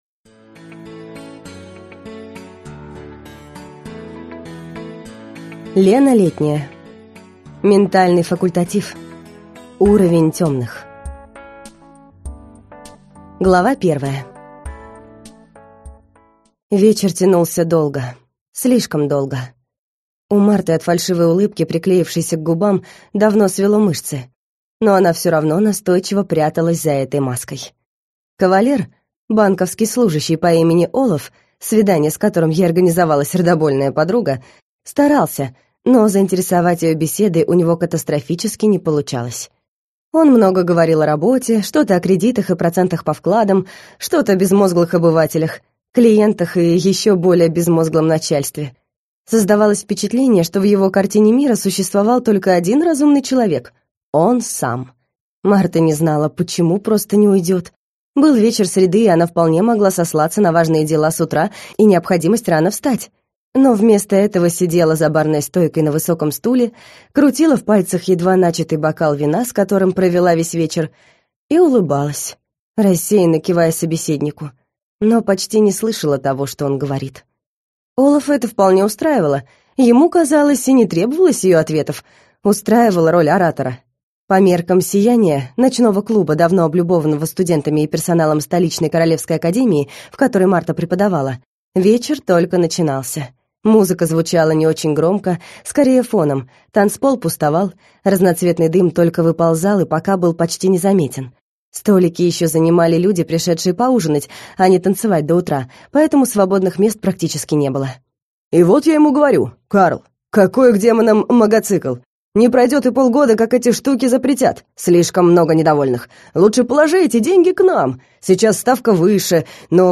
Аудиокнига Ментальный факультатив. Уровень темных | Библиотека аудиокниг